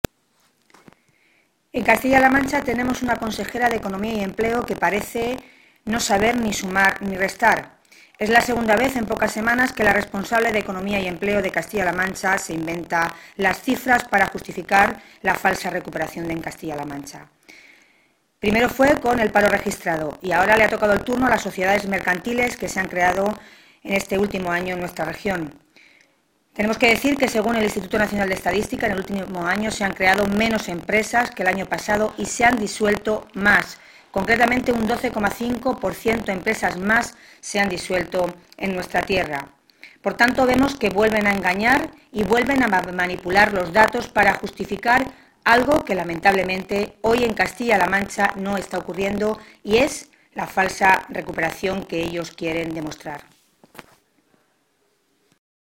La portavoz de Empleo del PSOE en las Cortes de Castilla-La Mancha, Milagros Tolón, ha señalado hoy que da toda la sensación de que en la región “tenemos una consejera de Economía y Empleo, Carmen Casero,que no sabe sumar ni restar, pero lo cierto es que lo que hace es engañar y manipular las cifras para justificar algo que lamentablemente no se está produciendo en Castilla-La Mancha, que es la recuperación económica”.
Cortes de audio de la rueda de prensa